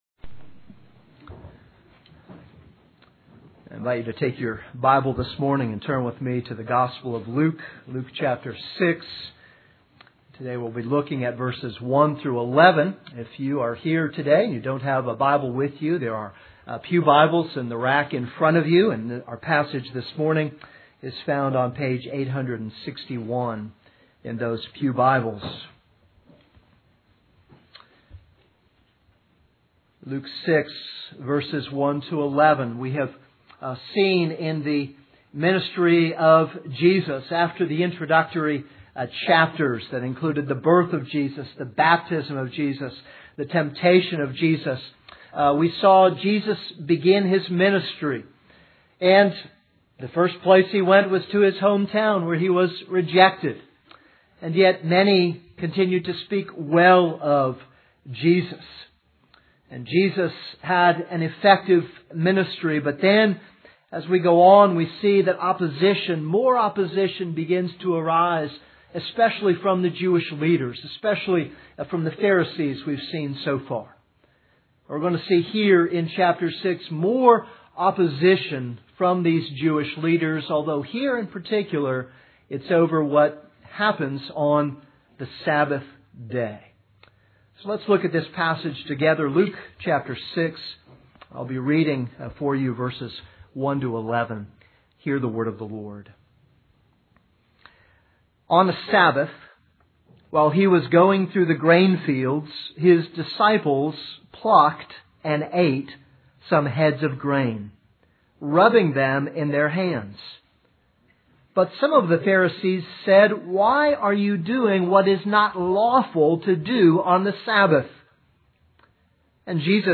This is a sermon on Luke 6:1-11.